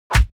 playerKnockback.wav